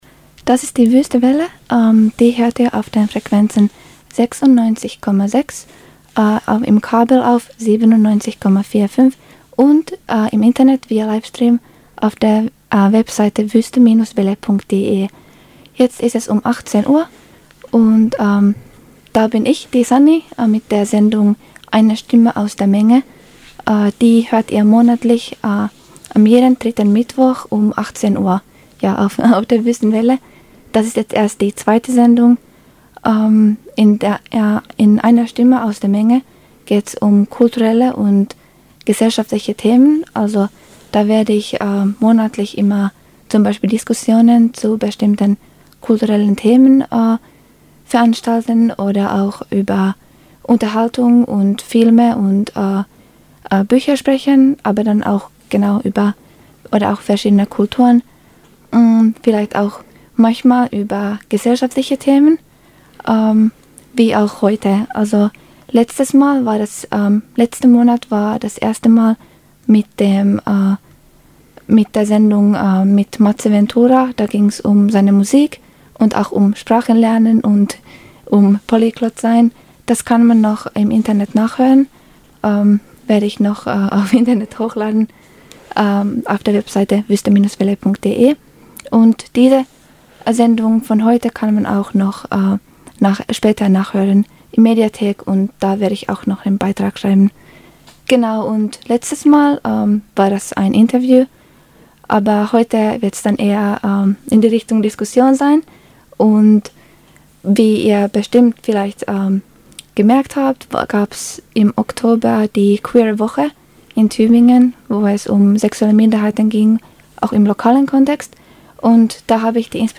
Dabei schilderten wir auch eigene Erfahrungen und spielten zwischendurch einige von unseren queeren Lieblingsliedern.